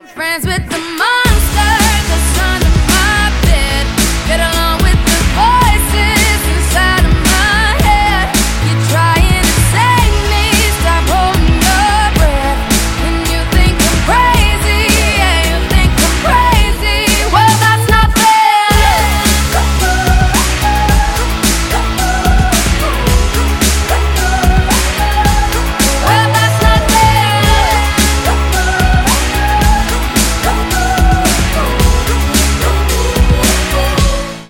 громкие
женский вокал
Хип-хоп
заводные
dance